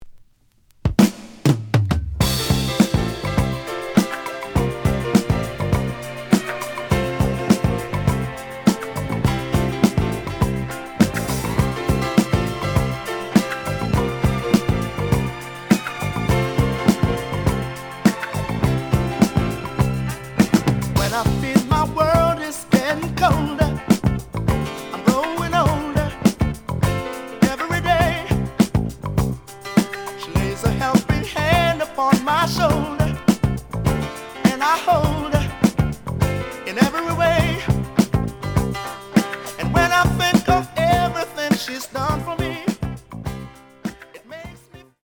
The audio sample is recorded from the actual item.
●Genre: Soul, 70's Soul
Edge warp.